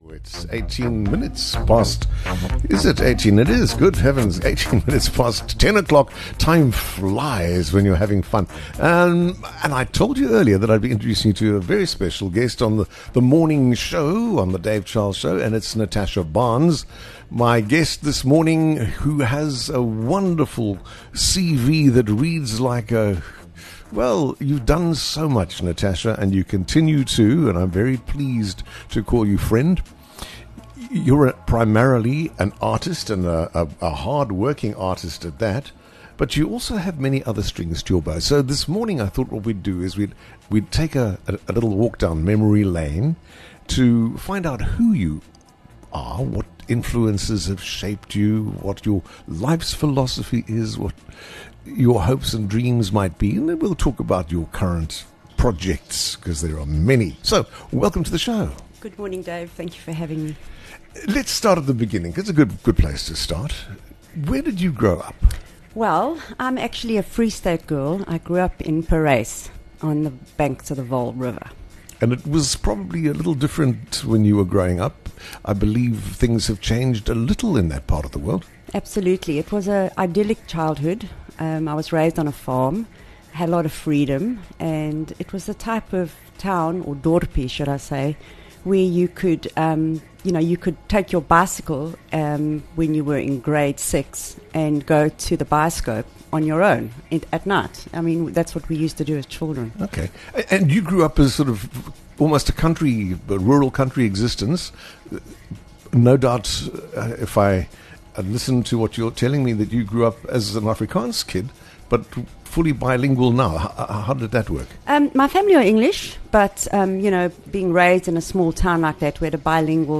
into the studio to share her journey and the special stories that have shaped her path